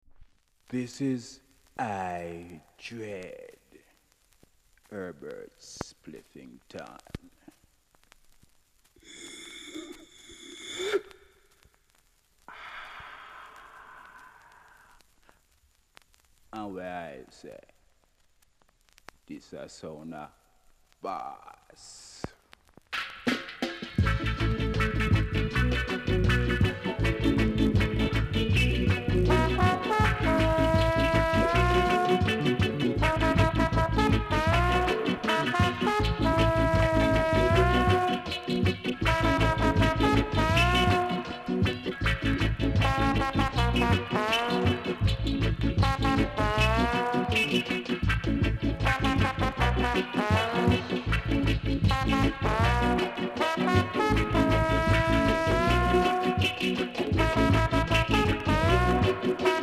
※少し音が濁って聴こえます。